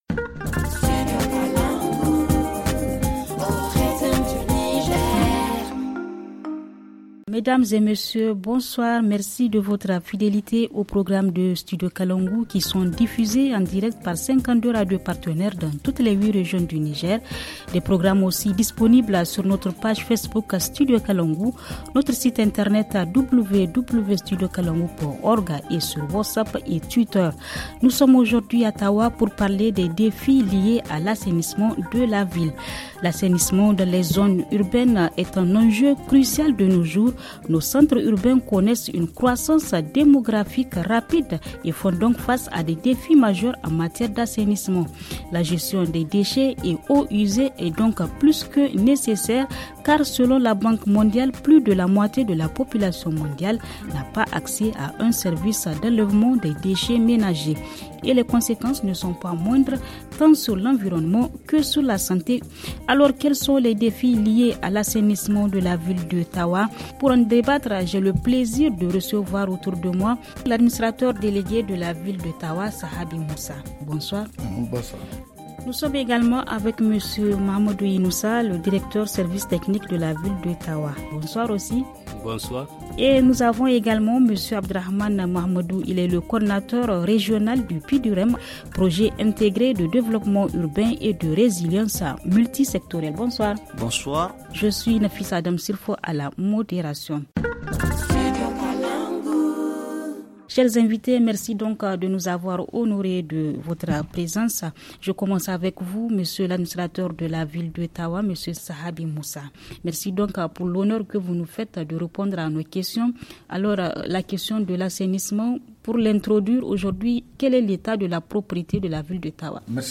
FR Le forum en français Télécharger le forum ici.